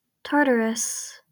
In Greek mythology, Tartarus (/ˈtɑːrtərəs/